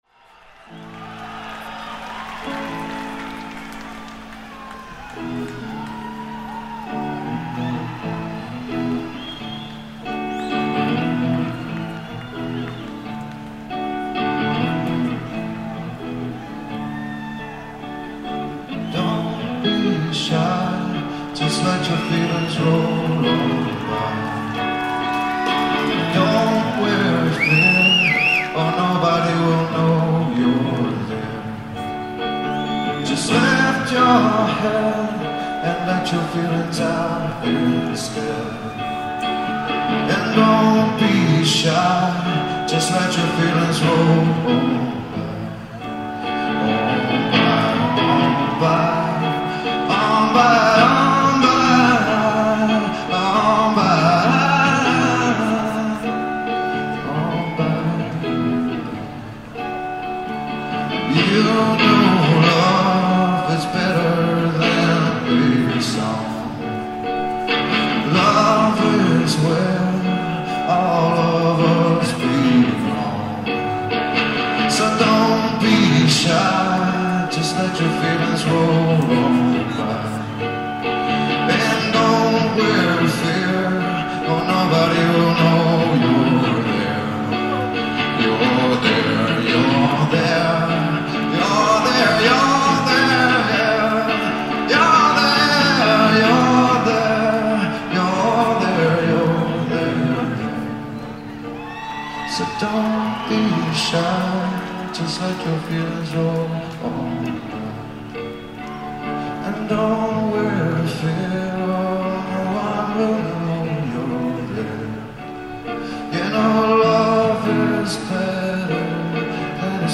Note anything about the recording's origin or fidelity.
10/25/00 - San Diego Sports Arena: San Diego, CA